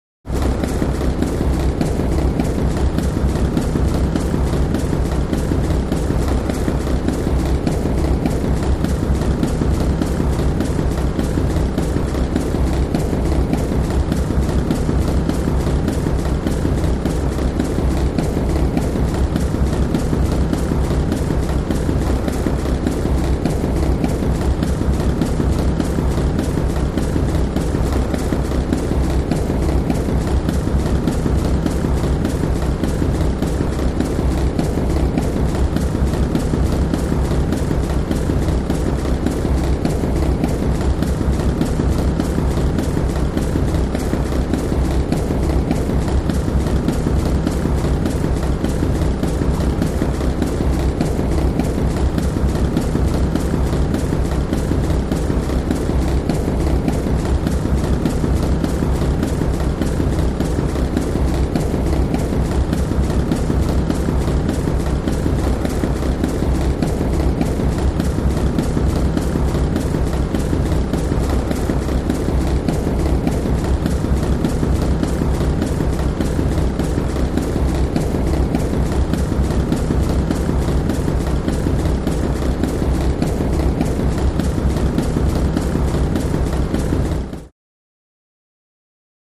ConveyerBeltRunLow PE276101
Conveyer Belt 1; Conveyer Belt Moving; Motor / Fan Noises, Rhythmically Repeating Low Frequency Flapping, Close Perspective.